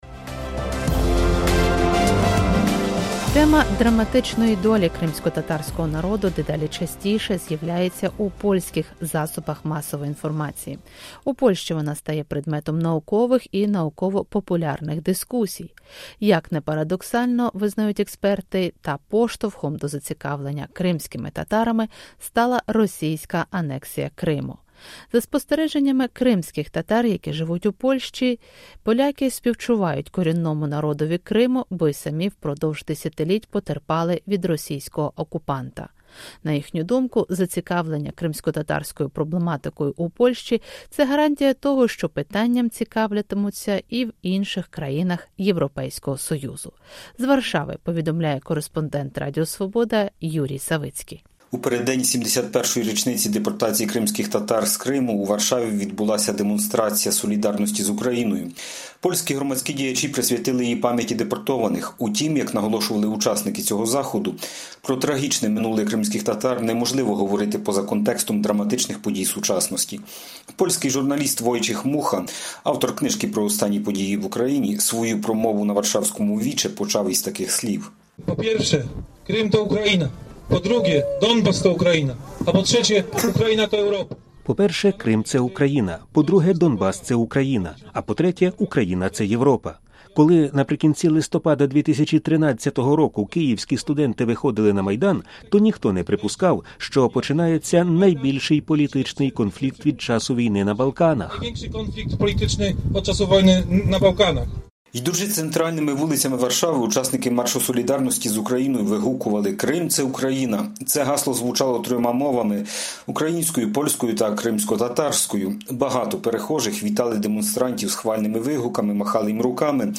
Митинг в 71-ю годовщину депортации крымских татар в Варшаве, 17 мая 2015 года
Идя по центральным улицам Варшавы, участники Марша солидарности с Украиной выкрикивали «Крым – это Украина!». Этот лозунг звучал на трех языках – украинском, польском и крымскотатарском.